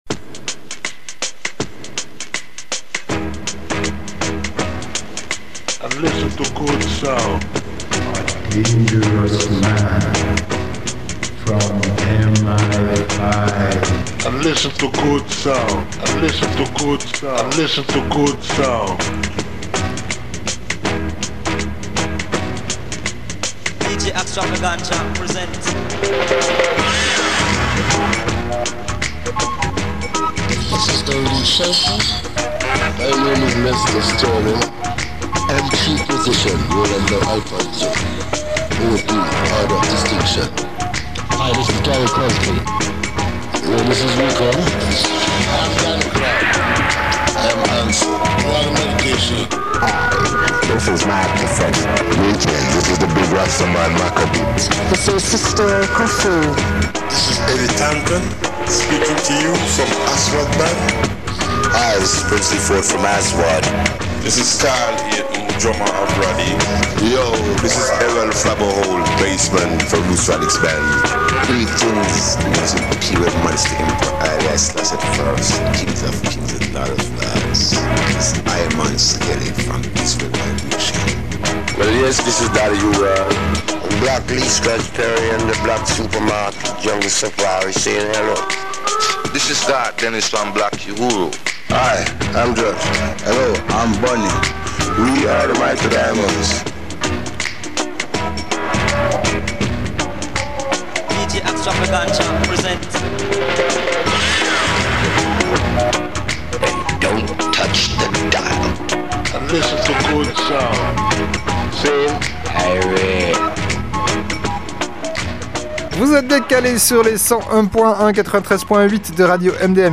radio show !